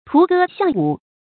涂歌巷舞 tú gē xiàng wǔ
涂歌巷舞发音